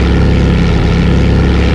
wasp.wav